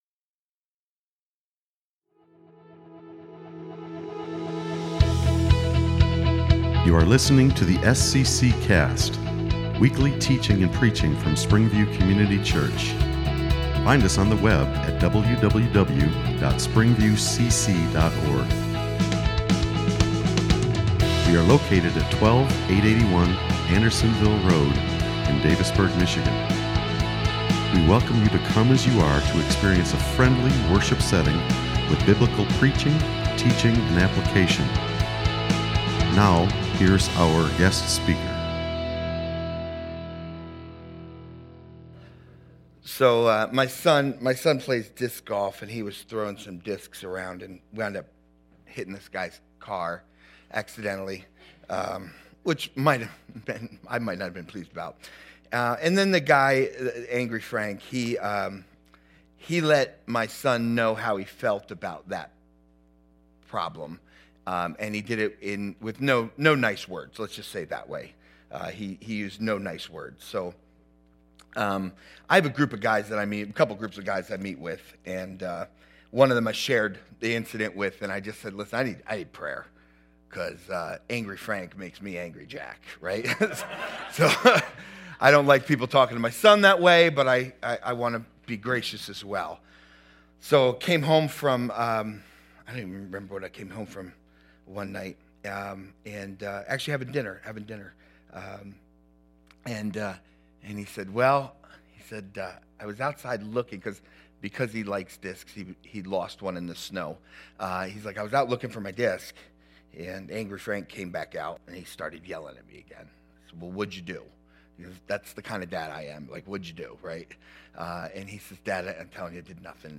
Sermons | SPRINGVIEW COMMUNITY CHURCH